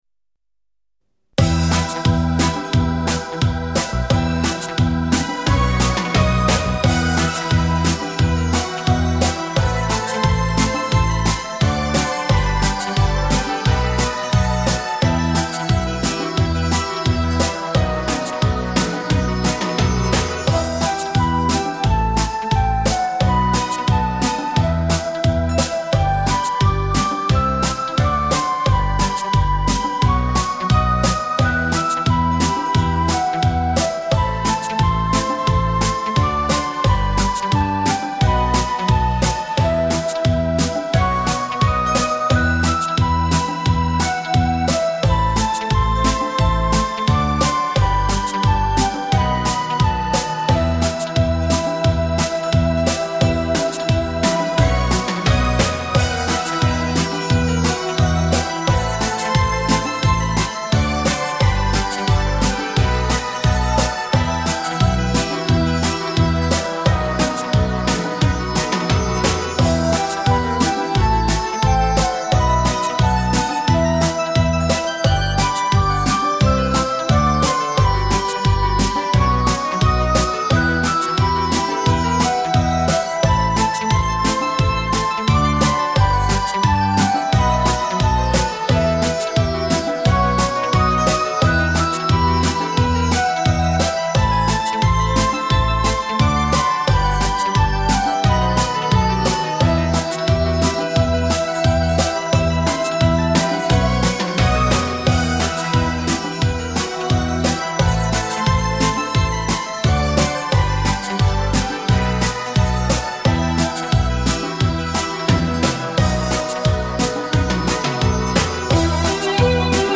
本碟分别收录了探戈、伦巴和布鲁斯等经典热门曲目，让熟悉的旋律伴你轻盈起舞，在舞步中重温流金岁月的激情与浪漫。